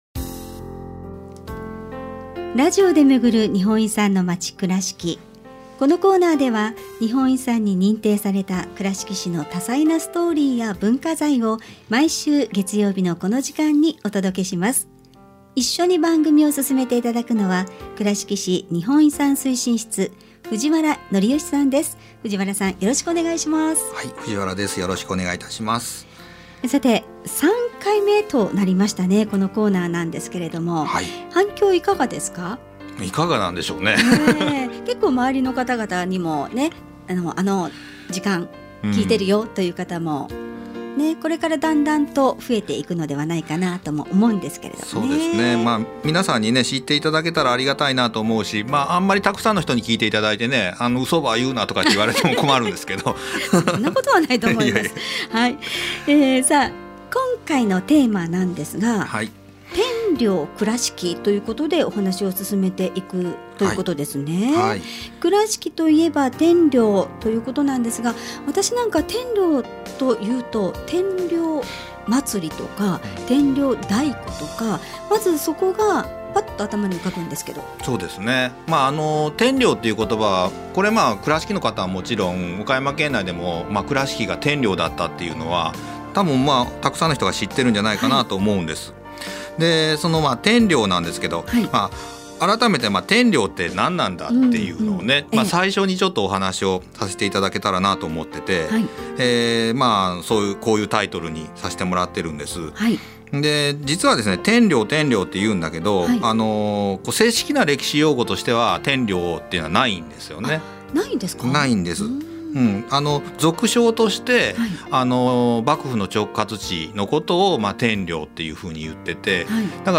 平成31年4月～令和2年3月の間、FMくらしきのワイド番組「モーニングくらしき」内で、倉敷市の日本遺産を紹介するコーナー「ラジオで巡る日本遺産のまち くらしき」を放送しました。